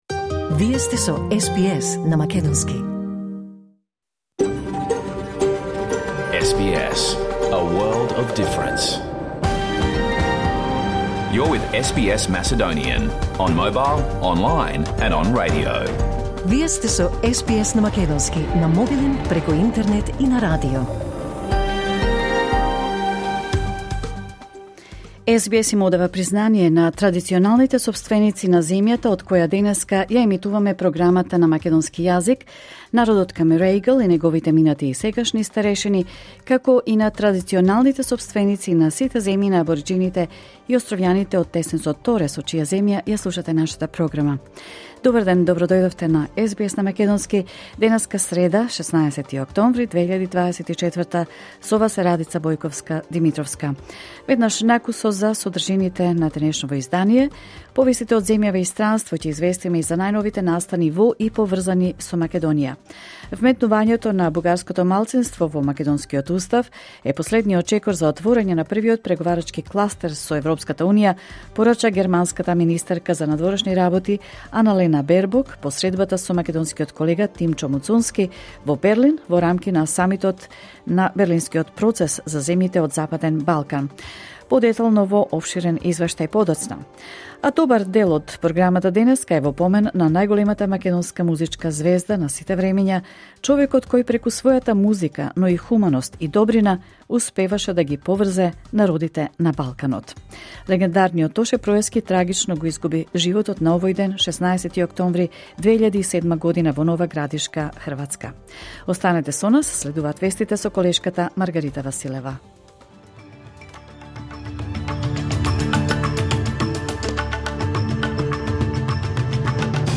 SBS Macedonian Program Live on Air 16 October 2024